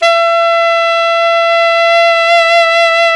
SAX ALTOMP0I.wav